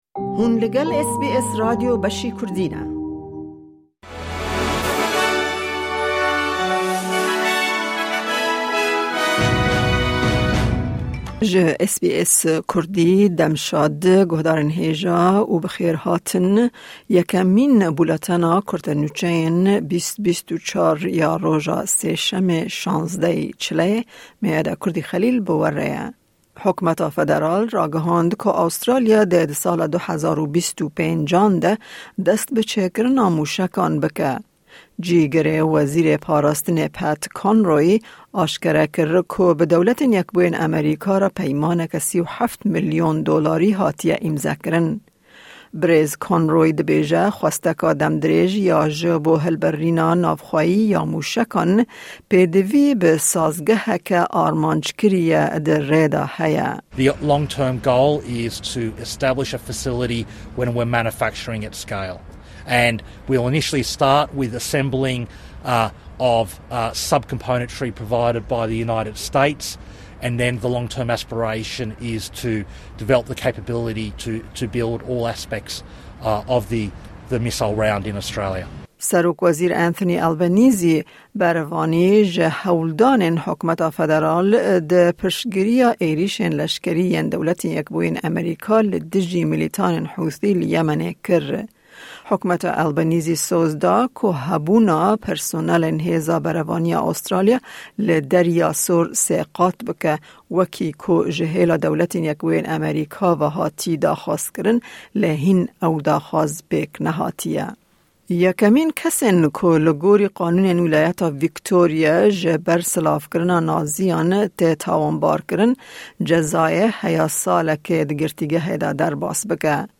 Kurte Nûçeyên roja Sêşemê 16î Rêbendana 2024